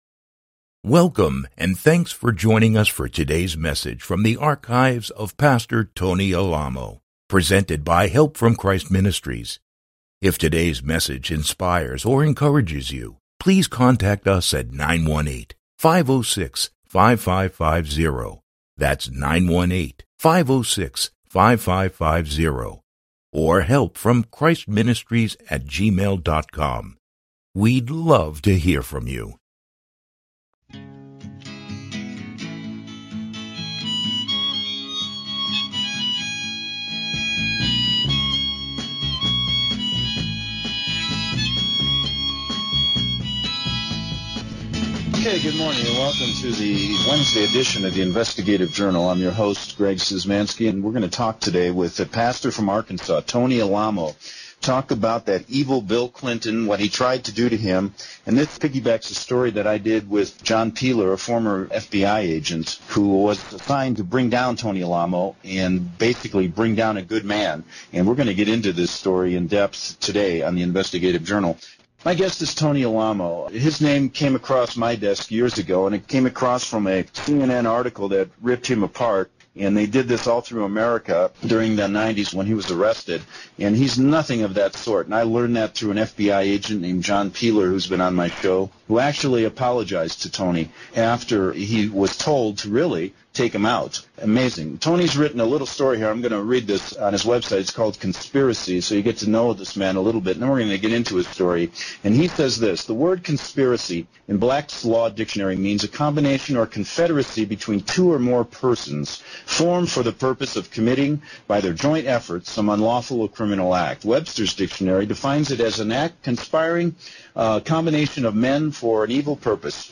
Talk Show Episode
Tony Alamo Interview Part 1